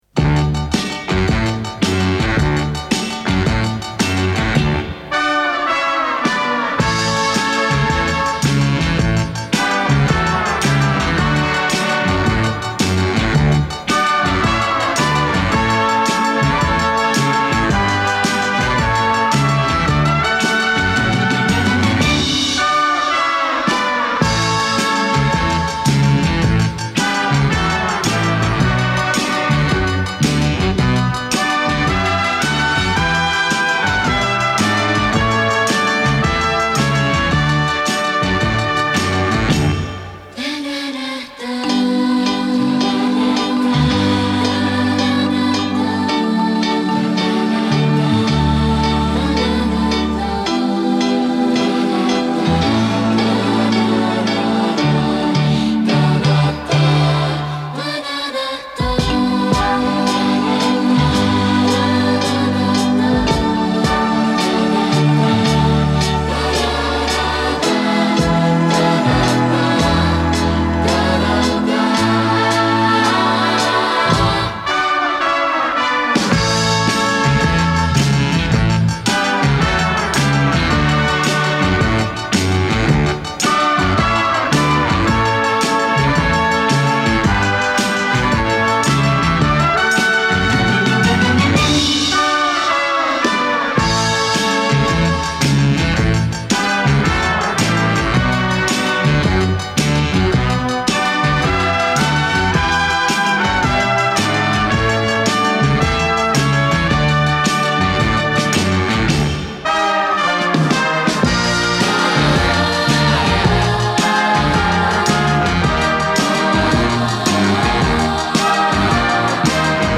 kontsert.mp3